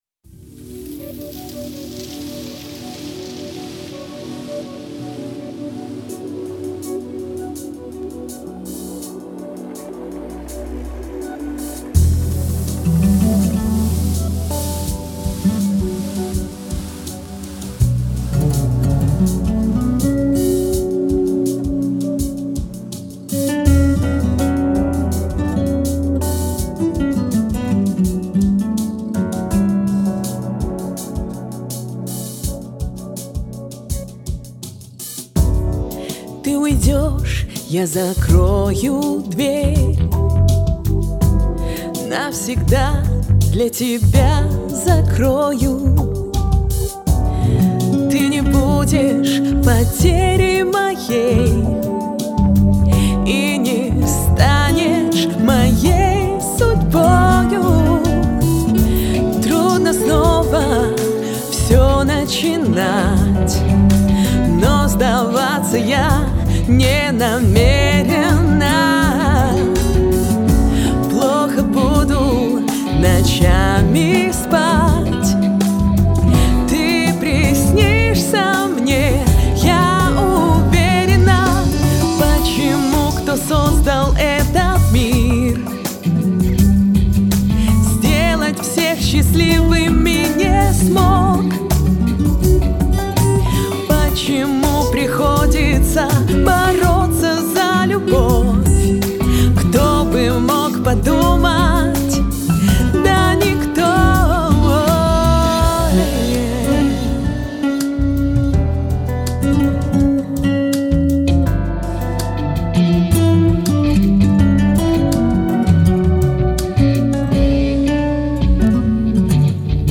Сразу извиняюсь за гитары.Конечно,хотелось бы,чтобы партии были сыграны гитаристом,да на гитаре.но,тут уж что есть-то есть.
Ну и мои действия: эквалайзер (4-х полос не хватило, пришлось двумя эквалайзерами ) и лимитер просто на всякий случай Вложения takaya_istoria_red.mp3 takaya_istoria_red.mp3 9,1 MB · Просмотры: 566